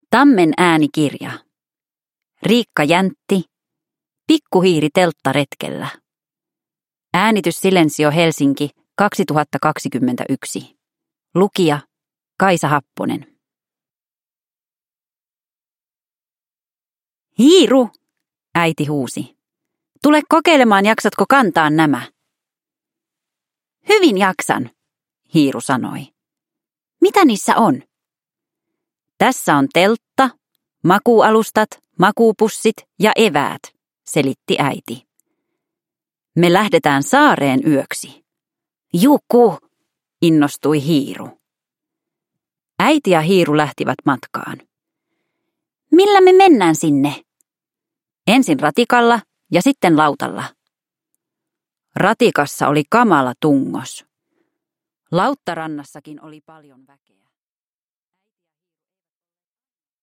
Pikku hiiri telttaretkellä – Ljudbok – Laddas ner